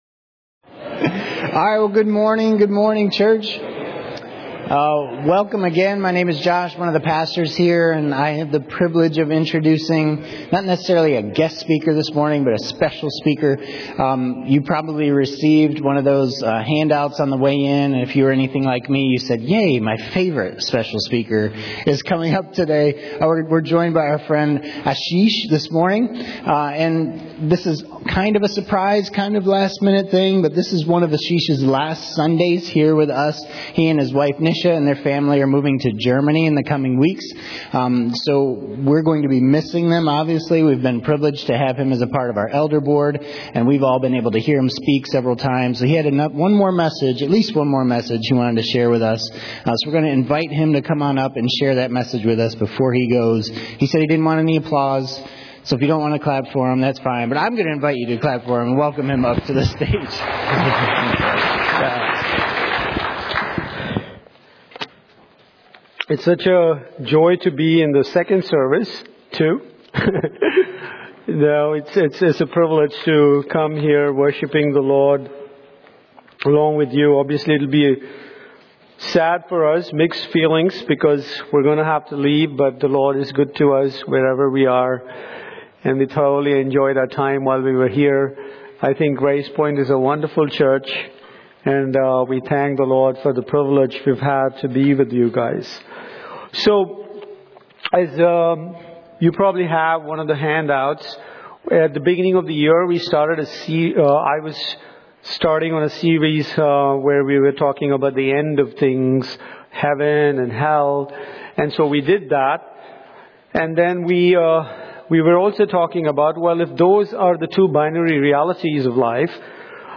Series: 2024 Sermons